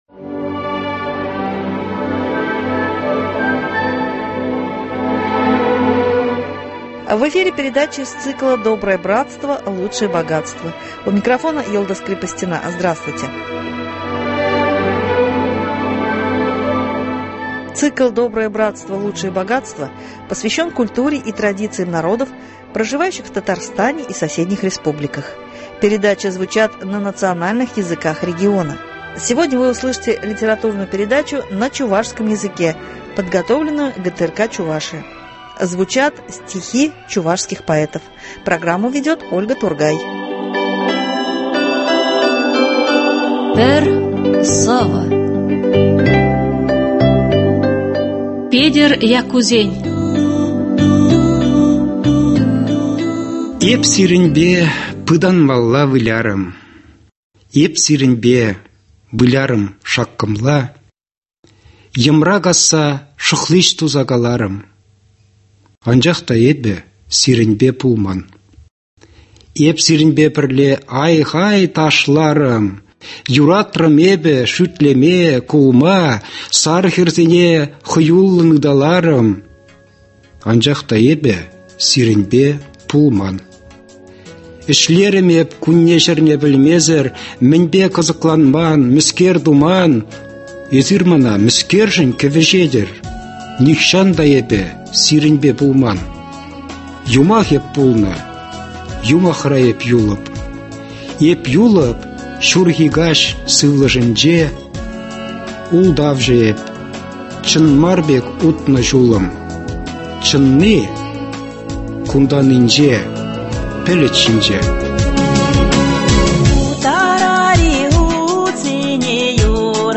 Цикл посвящен культуре и традициям народов, проживающих в Татарстане и соседних республиках, передачи звучат на национальных языках региона . Сегодня вы услышите литературную передачу на чувашском языке, подготовленную ГТРК «Чувашия».